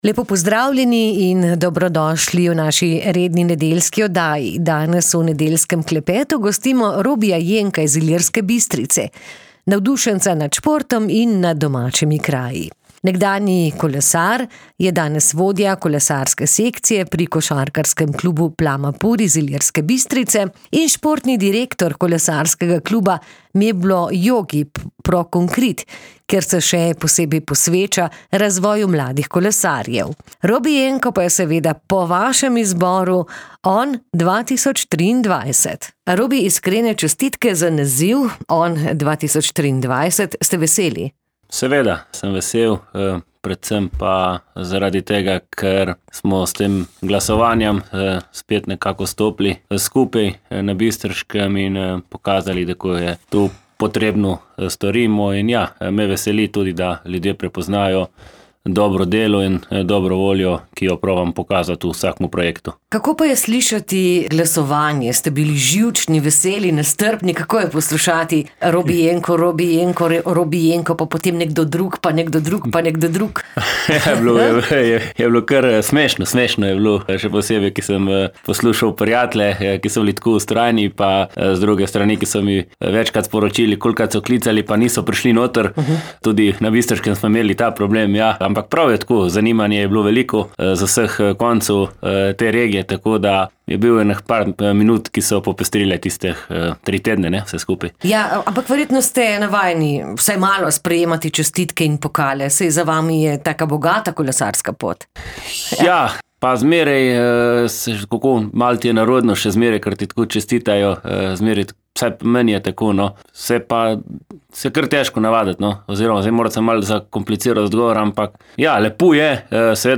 Nagrajenca smo povabili pred mikrofon.